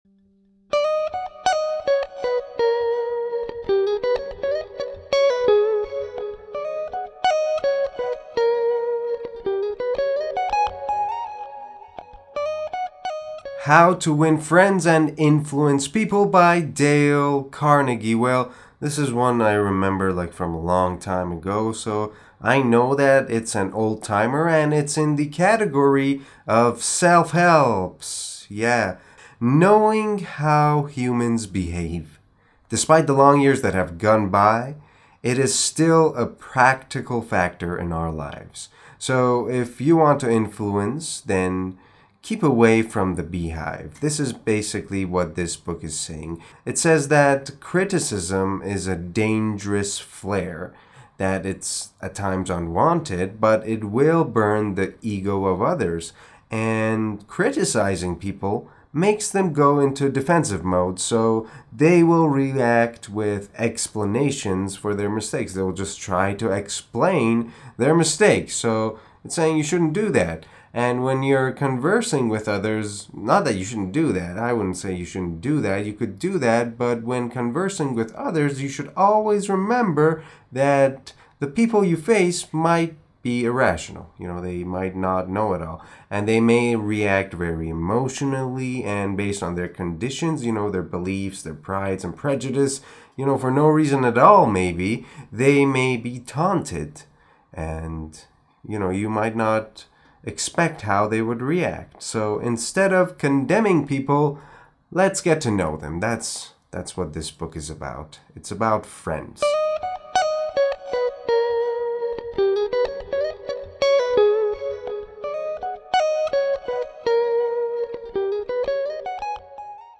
معرفی صوتی کتاب How To Win Friends And Influence People